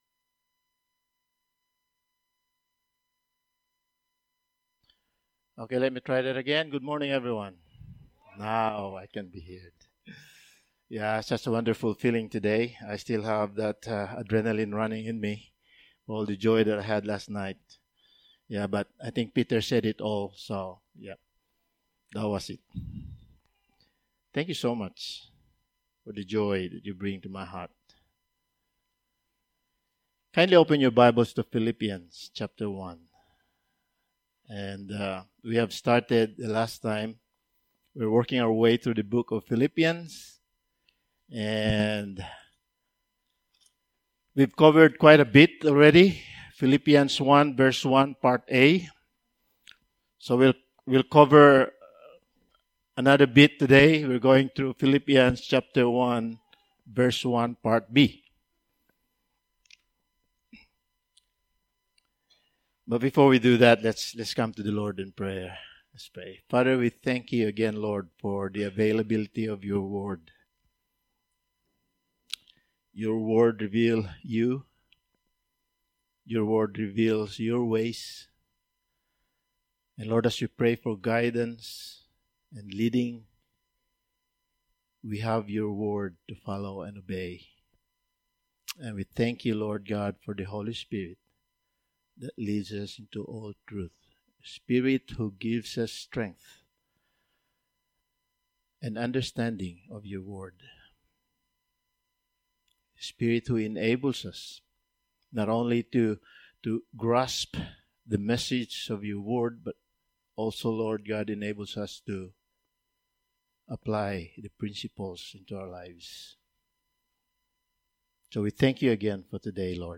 Passage: Philippians 1:1 Service Type: Sunday Morning